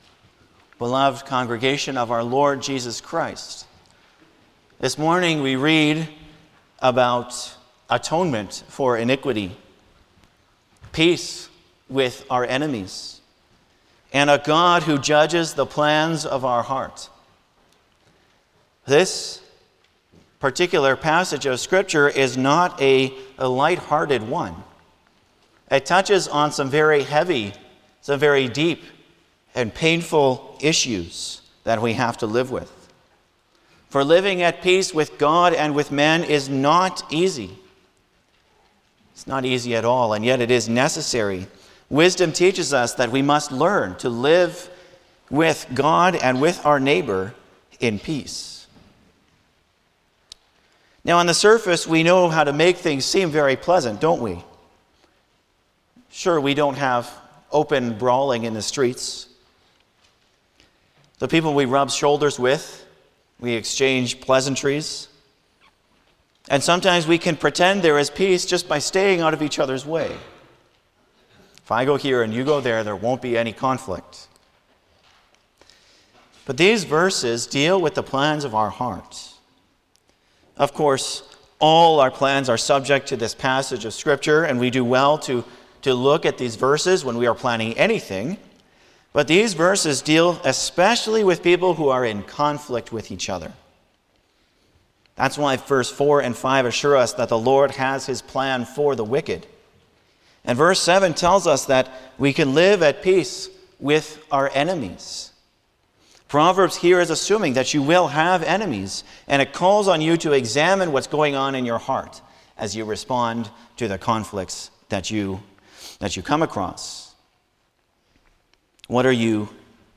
Passage: Proverbs 15:33 – 16:7 Service Type: Sunday morning
09-Sermon.mp3